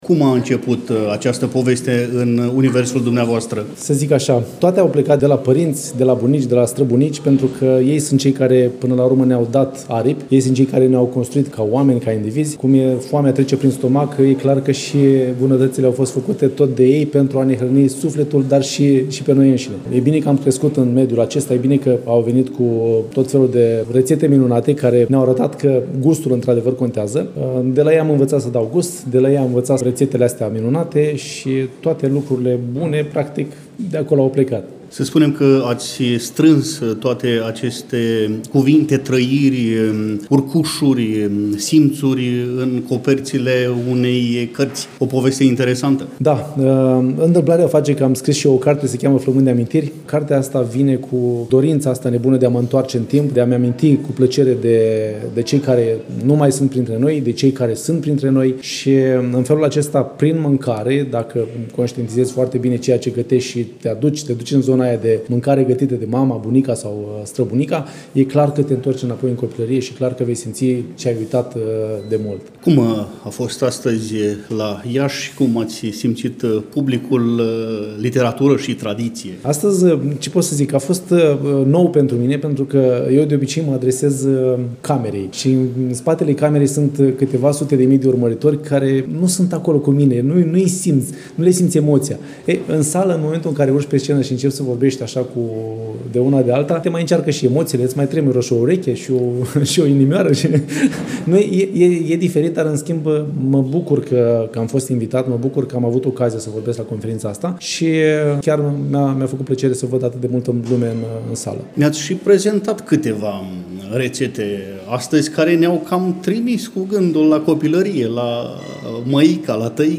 Relatăm de la ediția a X-a a Festivalului „Teodorenii”, eveniment desfășurat, la Iași, în perioada 10 – 12 decembrie 2024.
interviu încărcat de tradiție